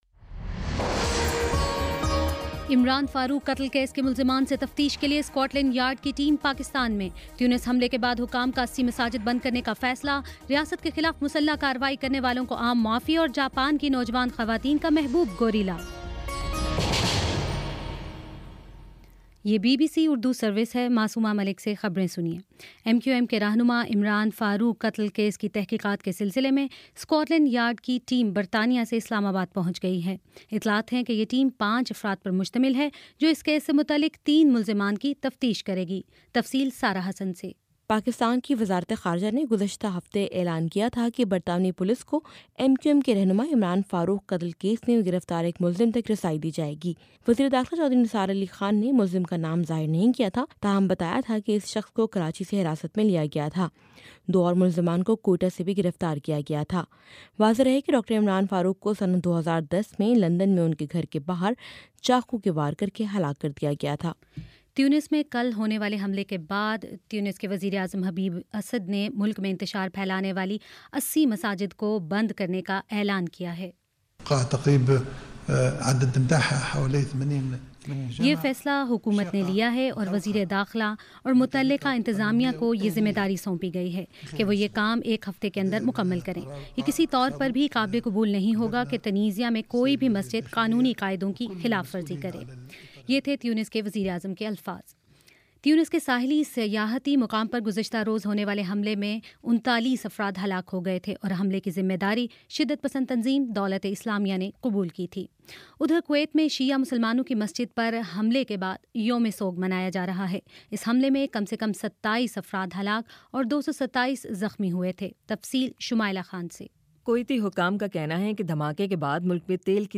جون 27: شام چھ بجے کا نیوز بُلیٹن